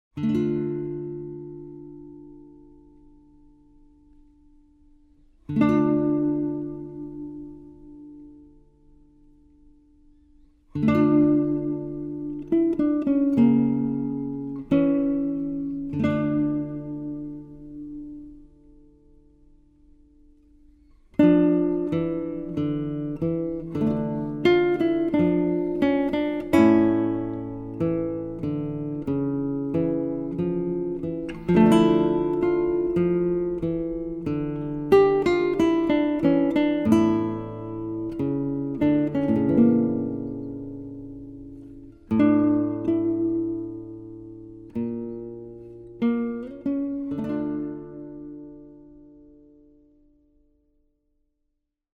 a beautiful, melancholic score